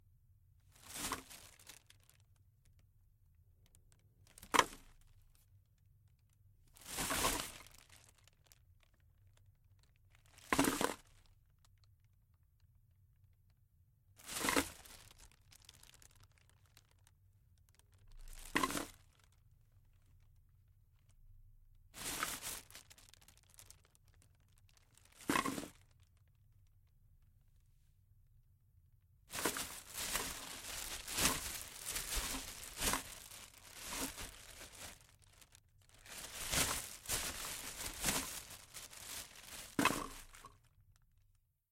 塑料袋中的铁罐
描述：将塑料袋中的罐子拿起来放下。未经处理的录音。
Tag: 平定 可以 可以 塑料 接送 霍霍 拨浪鼓 沙沙作响 沙沙声